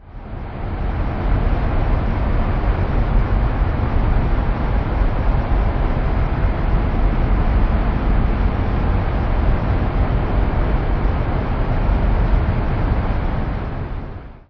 ore-leaching-plant.ogg